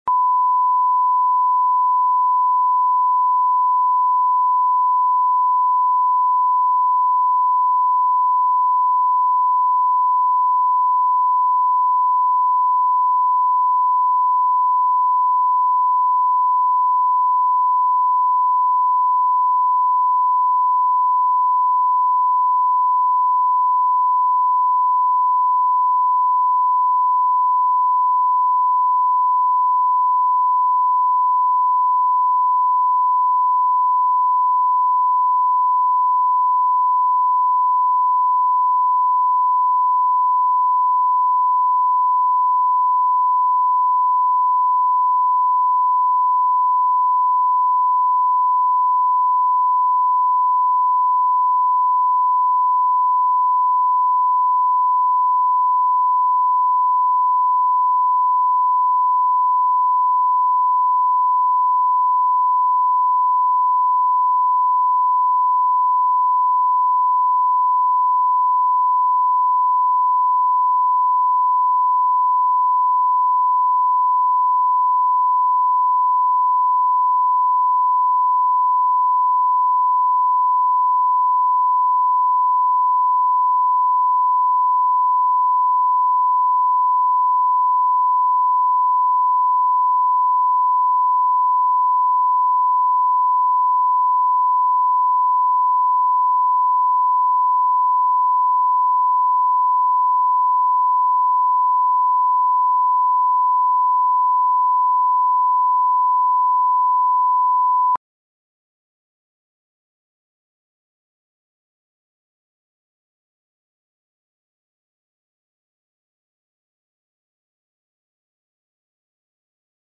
Аудиокнига Всего лишь замена | Библиотека аудиокниг